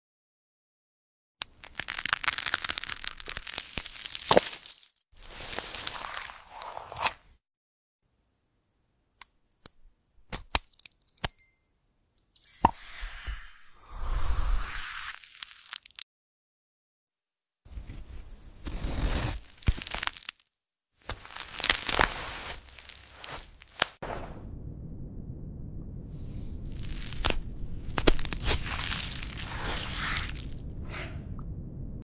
AI ASMR | Cut and sound effects free download
Cut and Slice some magical eggs today! Enjoy satisfying ASMR🥰!